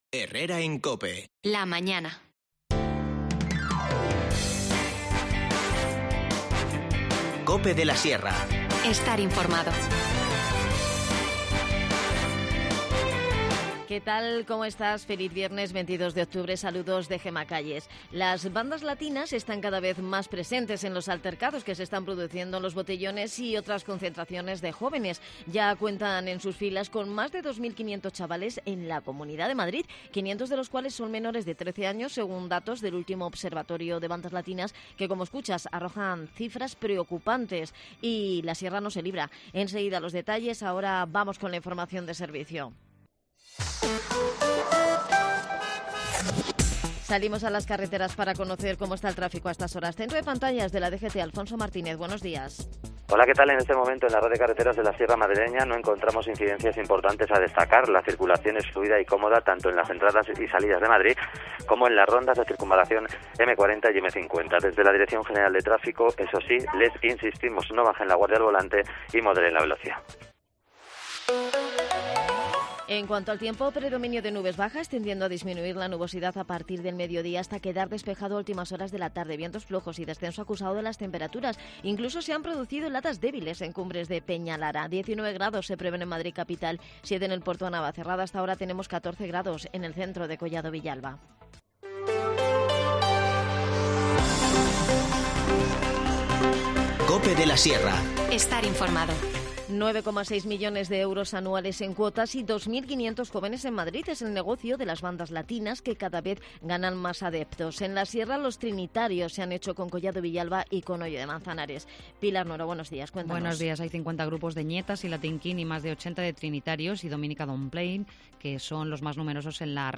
En el tiempo de Deporte en Herrera en COPE nos lo cuenta la concejal de Deportes, Mercedes Nuño.